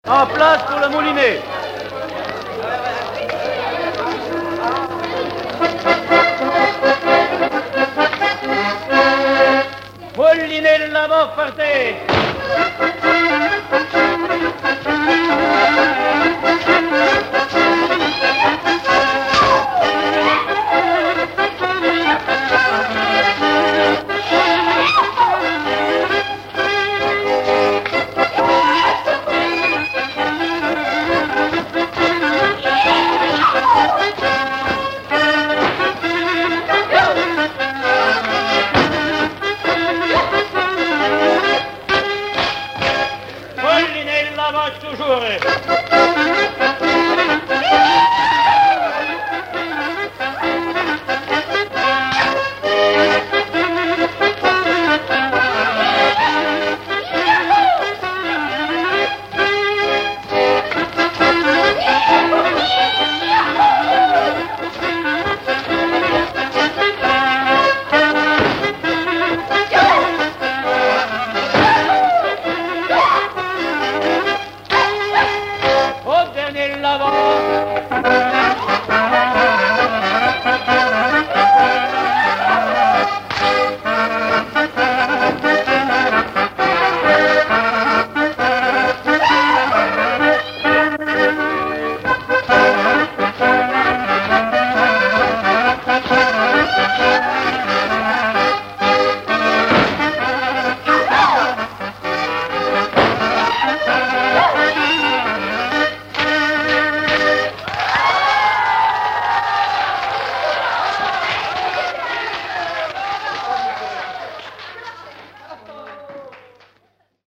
Mémoires et Patrimoines vivants - RaddO est une base de données d'archives iconographiques et sonores.
danse : quadrille : moulinet
Catégorie Pièce musicale inédite